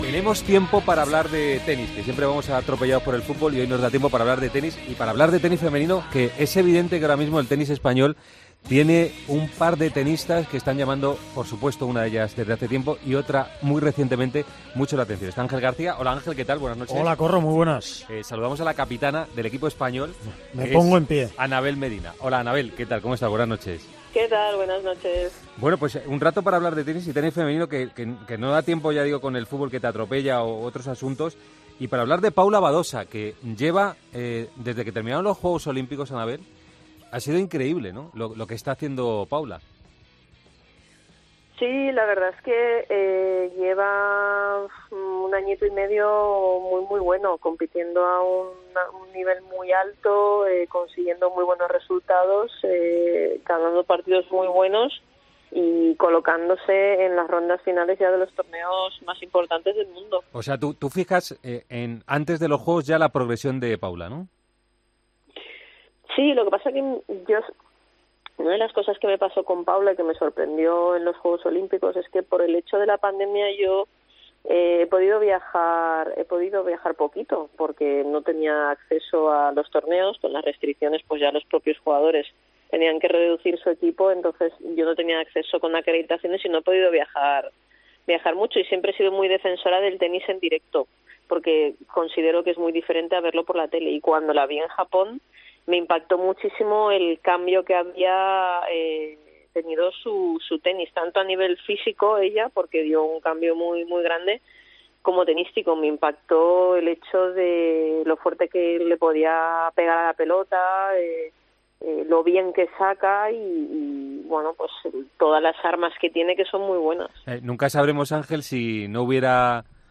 Anabel Medina, capitana del equipo de la, estuvo este sábado en los micrófonos de Tiempo de Juego donde analizó el momento que está atravesando Paula Badosa, cercana al número 1 del mundo.